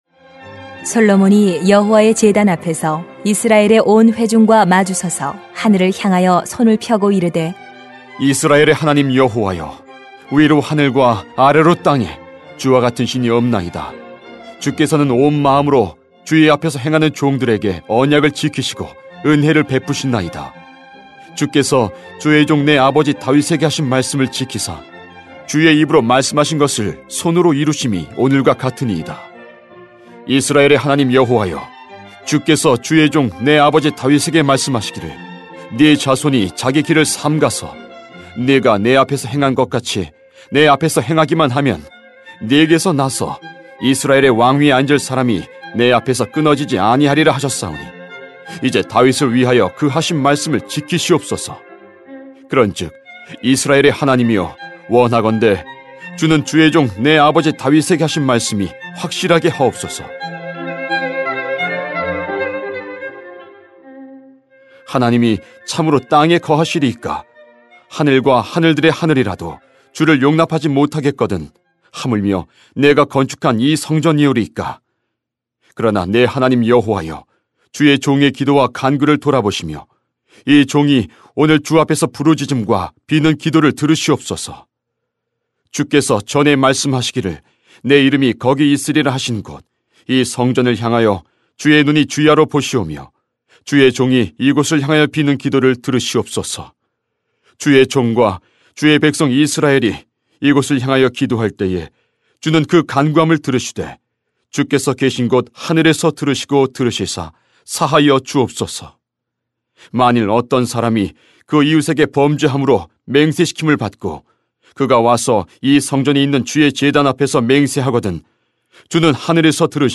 [왕상 8:22-32] 하나님은 약속을 이루시는 분이십니다 > 새벽기도회 | 전주제자교회